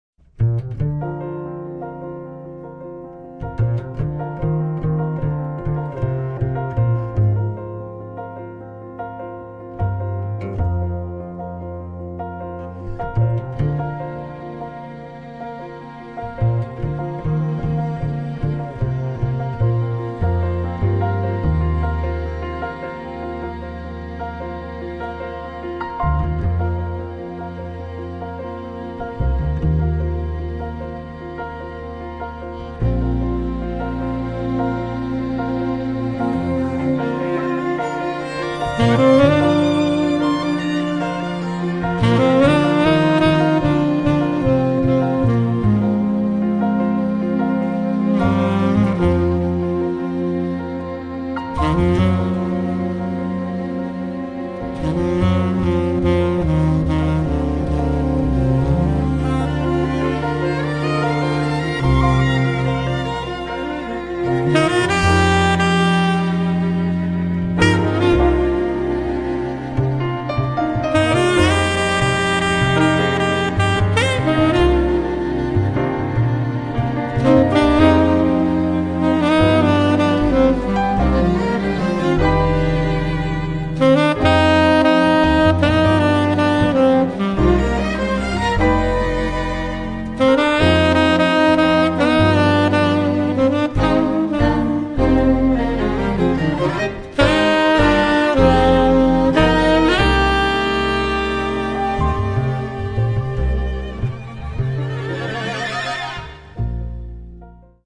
sassofoni
pianoforte
contrabbasso
violino
viola
violoncello